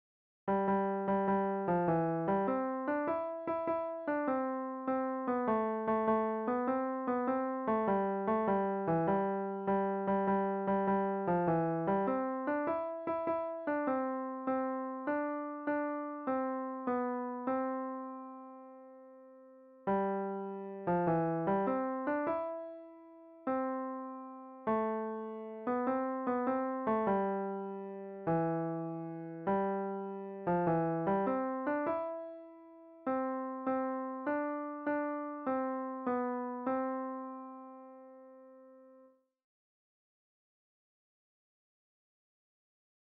'major', in a major key, 'C' Major, major tonality, major tonal environment